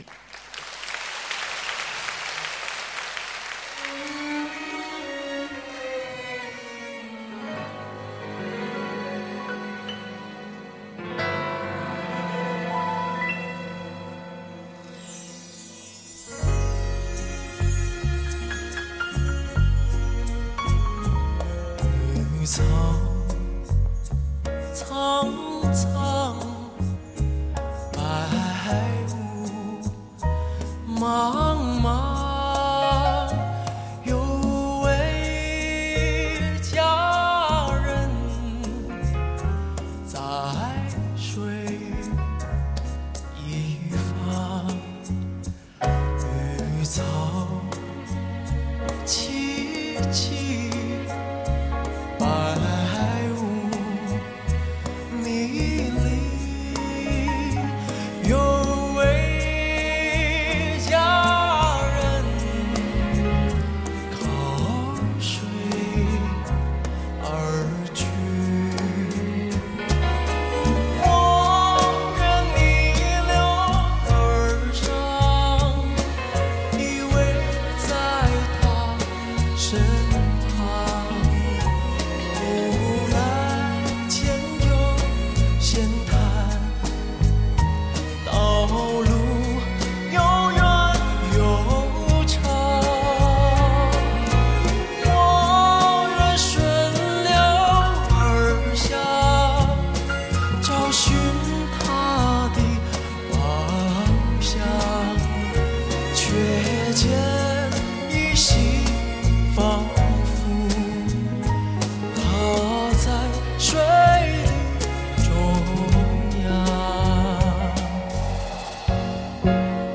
完美现场演绎 26首好歌
发烧级96kHz/24bit录音及混音